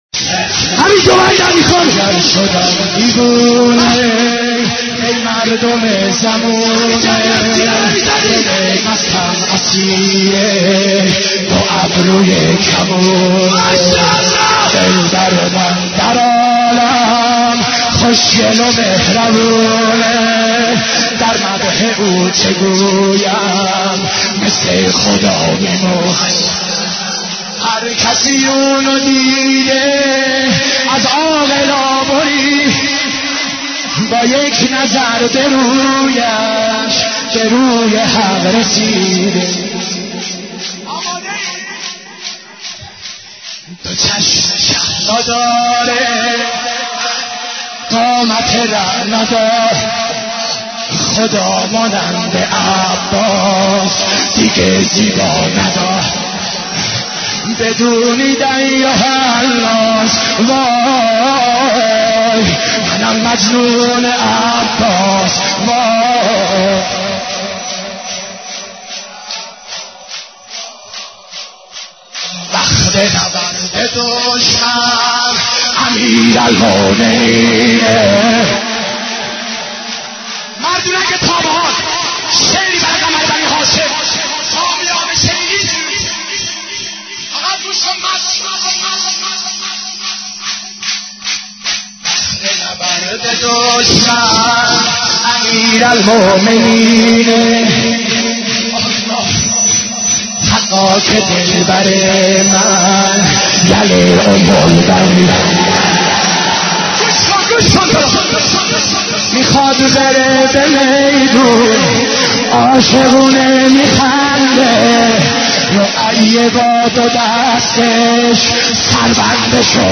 حضرت عباس ع ـ شور 19